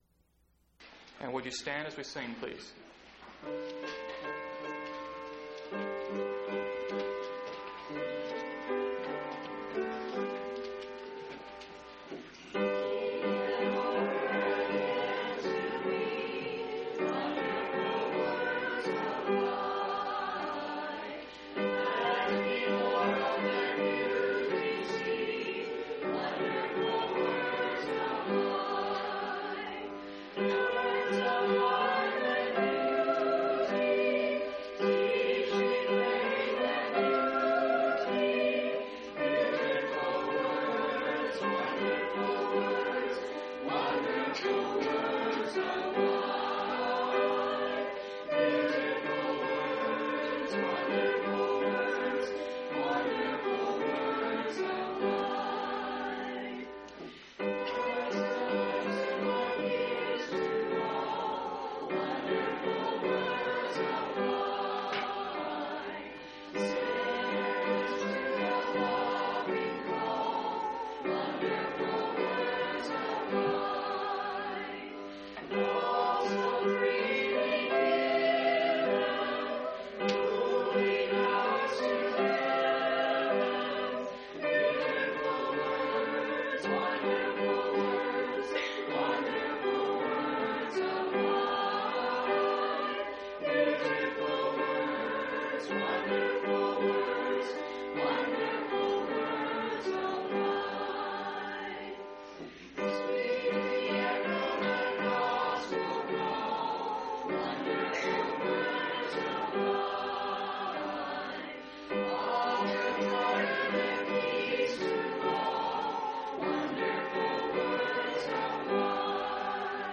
11/8/1992 Location: Phoenix Local Event